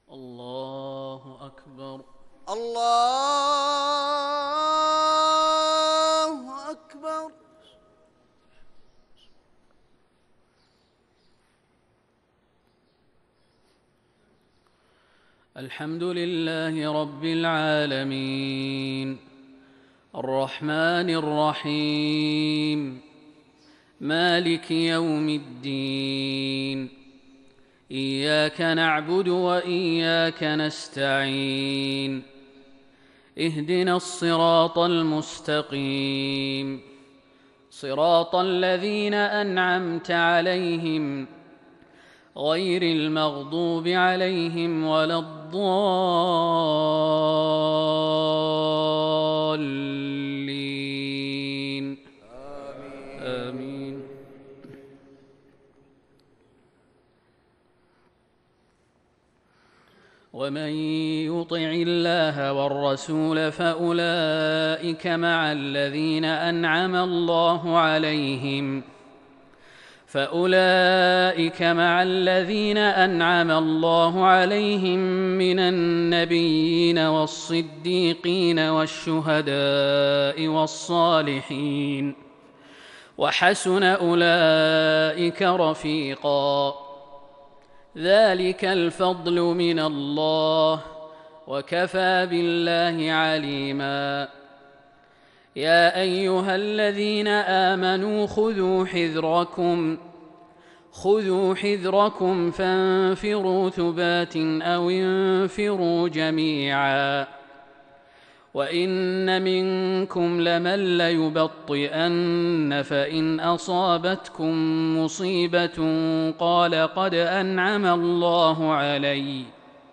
فجر 1-4-1442 تلاوه من سورة النساء Fajr Prayar from Surah An-Nisa | 16/11/2020 > 1442 🕌 > الفروض - تلاوات الحرمين